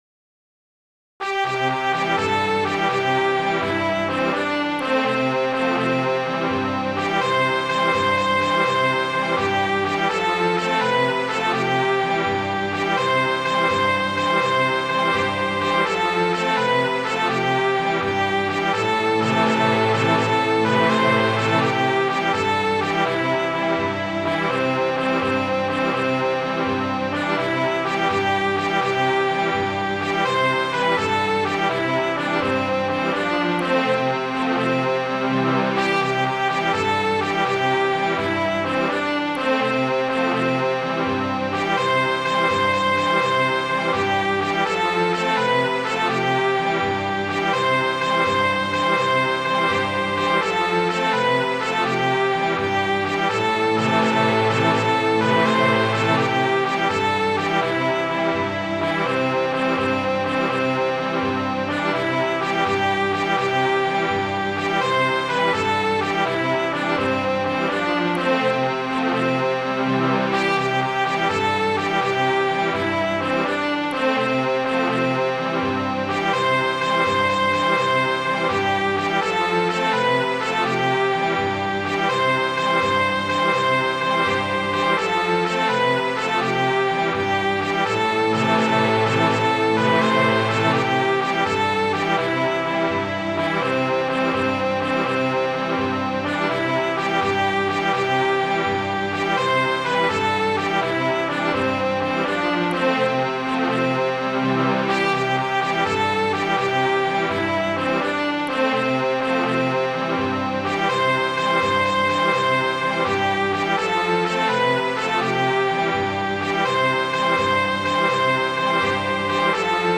Midi File, Lyrics and Information to The Battle of Saratogar